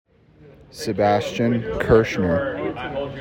⇓ Name Pronunciation ⇓